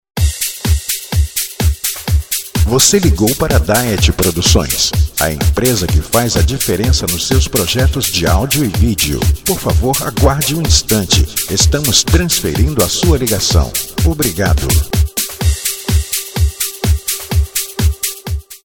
Sprechprobe: Industrie (Muttersprache):
voice-over